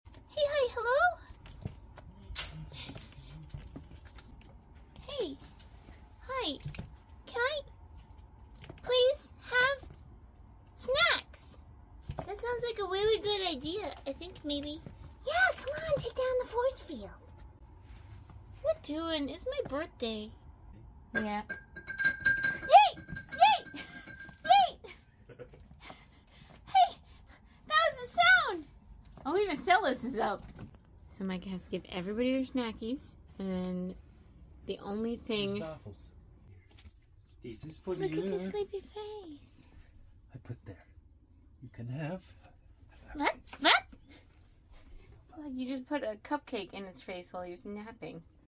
Cheep and Mochi are accidentaly trained to the sound of the cookie jar!